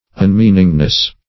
Un*mean"ing*ness, n.